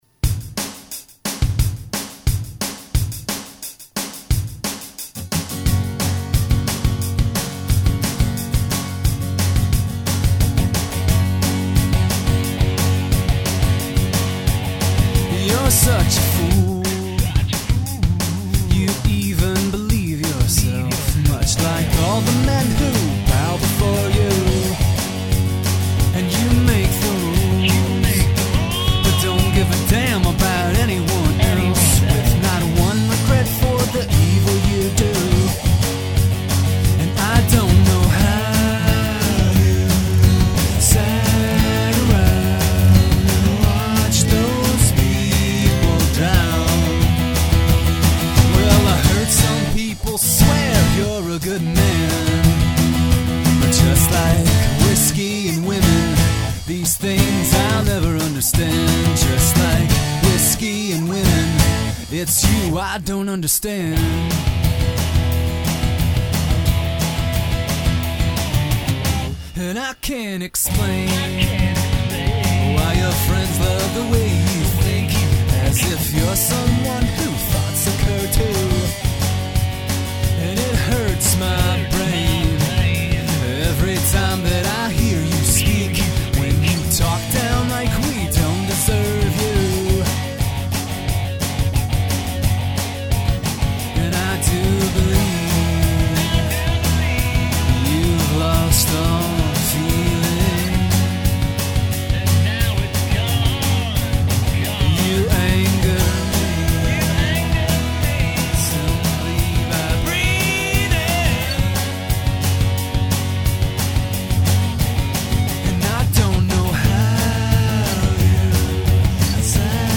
Album Version